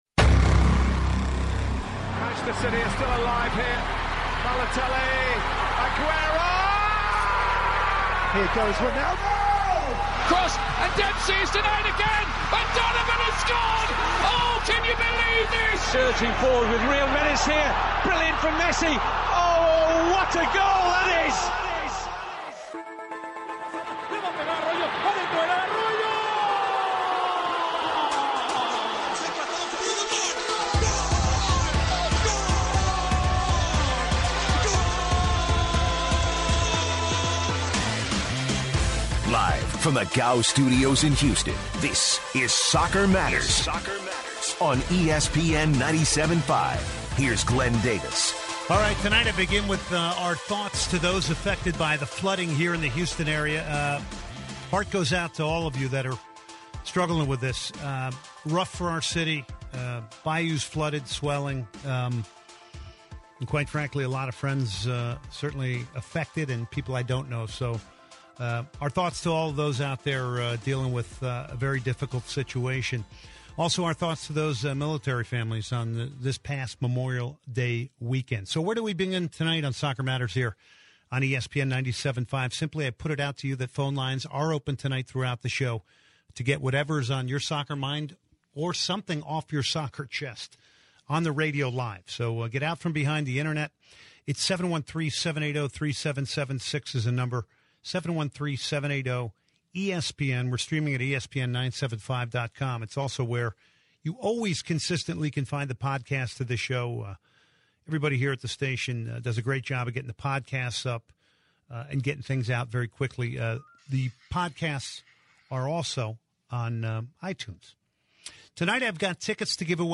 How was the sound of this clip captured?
taking phone calls plus discussion on managerial moves and possible replacements. Carlo Ancelotti is out at Real Madrid, who will take his place? Will Brendan Rodgers stay or go at Liverpool?